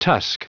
Prononciation du mot tusk en anglais (fichier audio)
Prononciation du mot : tusk